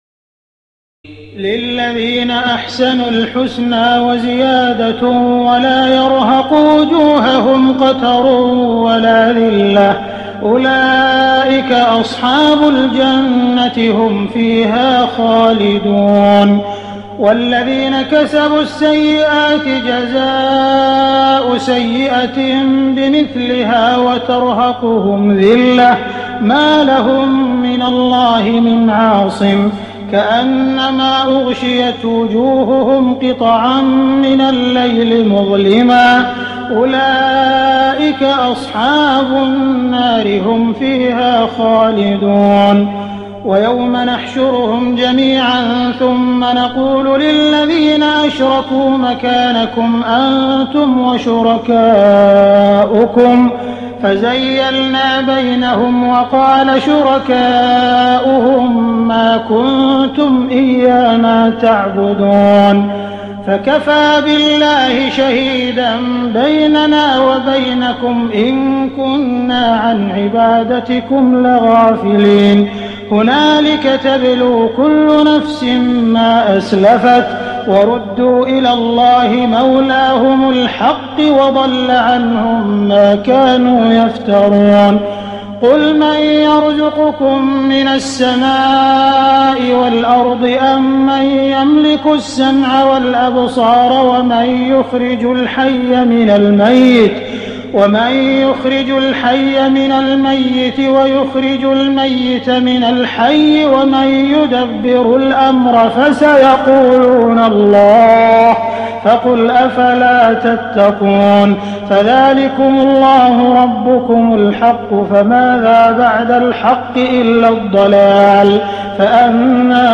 تراويح الليلة العاشرة رمضان 1419هـ من سورتي يونس (26-109) و هود (1-5) Taraweeh 10 st night Ramadan 1419H from Surah Yunus and Hud > تراويح الحرم المكي عام 1419 🕋 > التراويح - تلاوات الحرمين